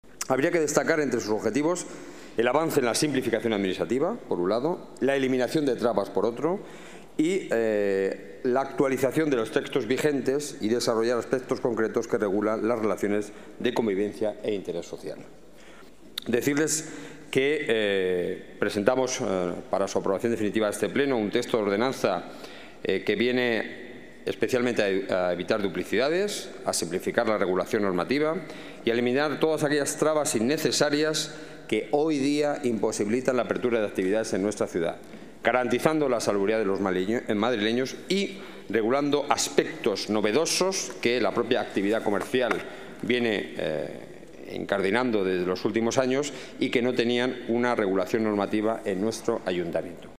Nueva ventana:Declaraciones del delegado de Seguridad y Emergencias, Enrique Núñez